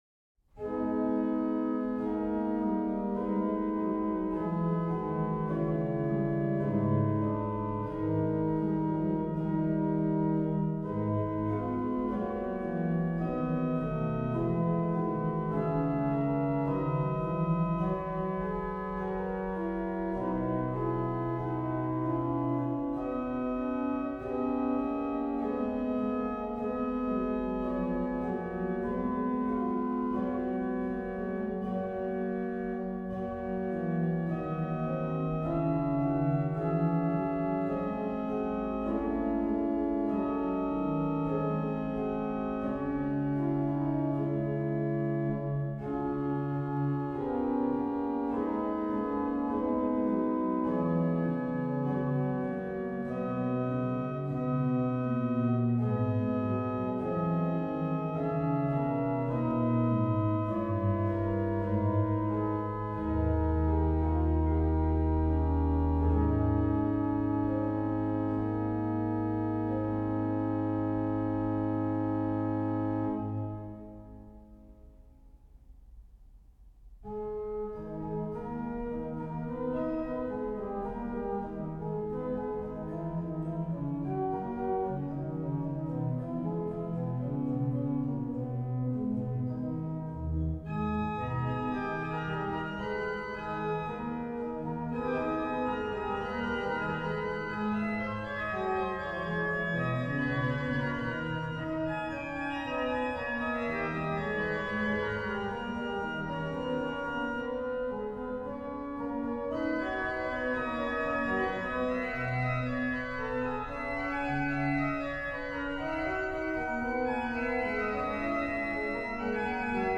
Venue 1736 Erasmus Bielfeldt organ, St. Wilhadi, Stade, Germany